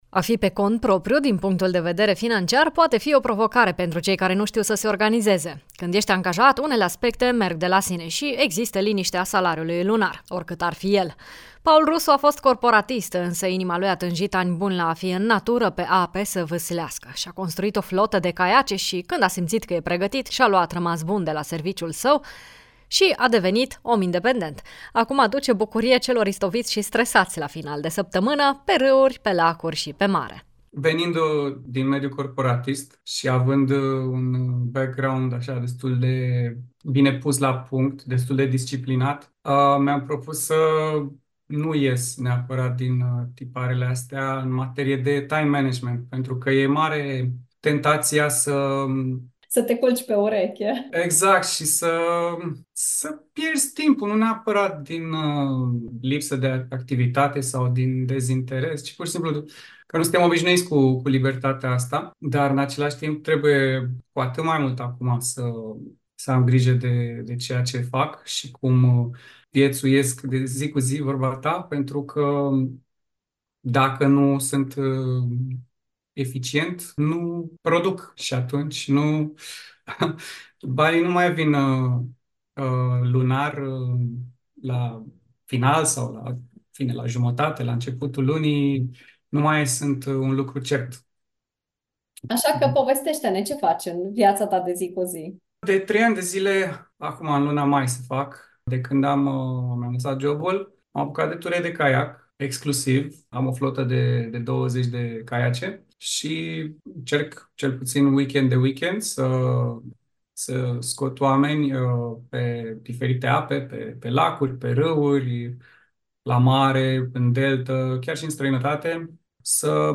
caiacist, instructor de snowboard: